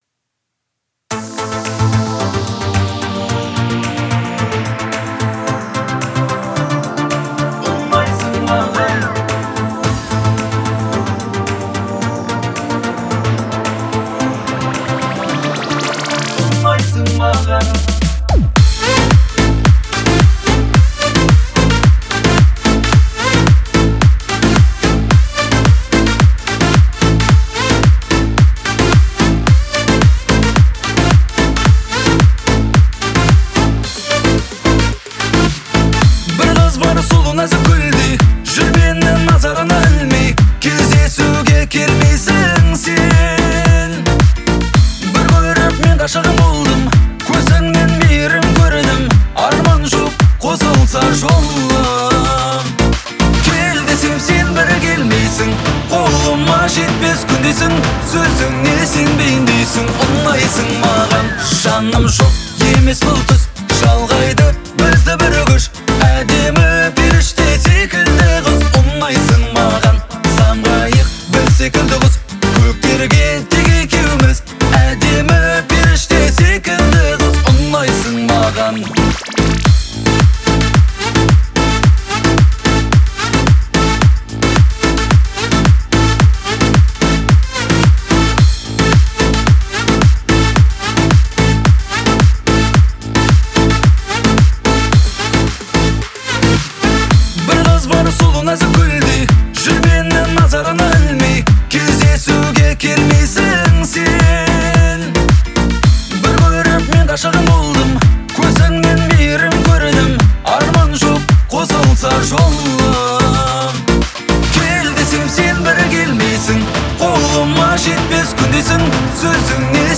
это романтичный поп-трек